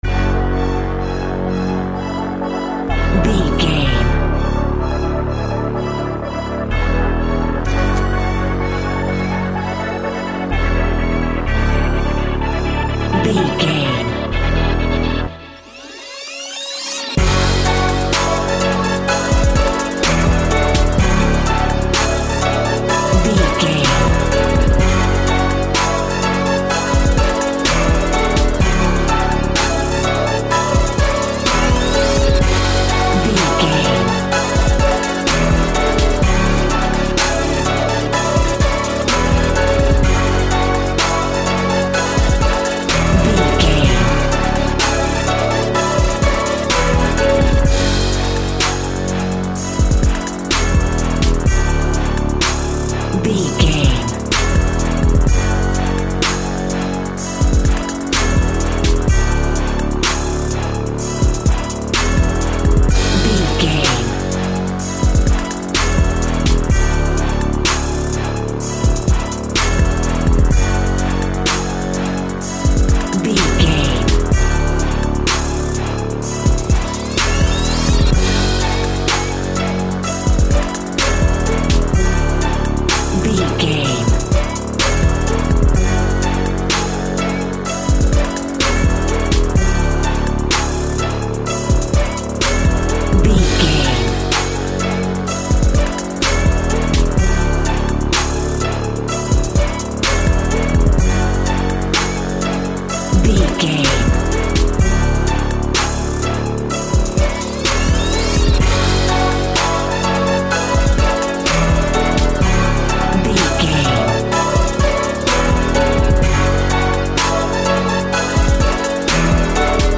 Aeolian/Minor
F#
instrumentals
chilled
laid back
groove
hip hop drums
hip hop synths
piano
hip hop pads